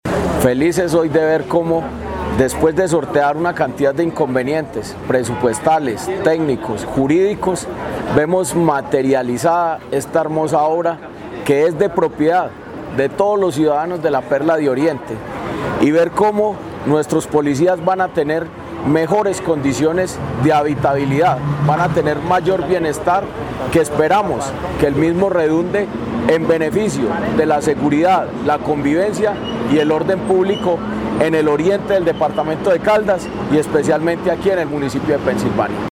Jorge Andrés Gómez Escudero, secretario de Gobierno de Caldas